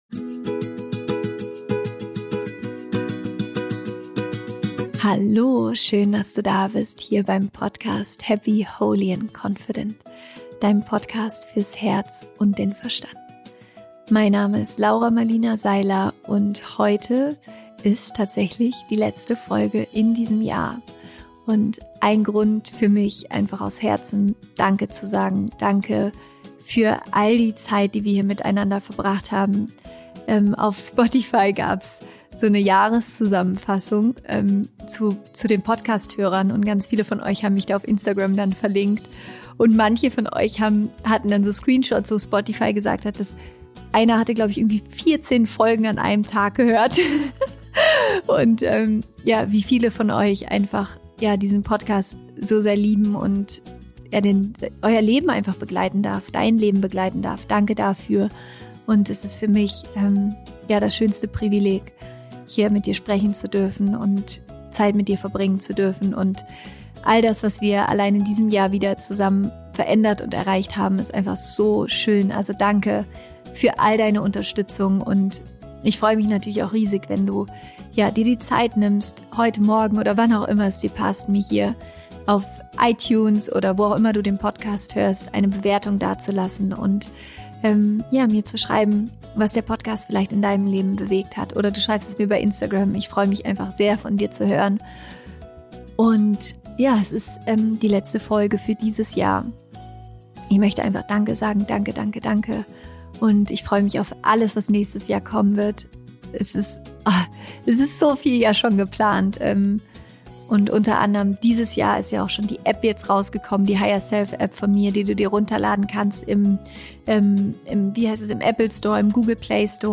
Meditation: Schließe dieses Jahr in Frieden ab